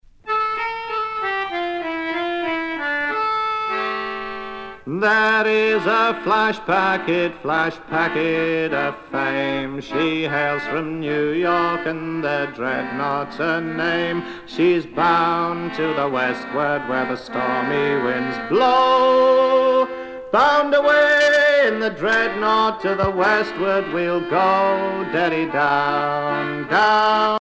Chants de marins traditionnels
Pièce musicale éditée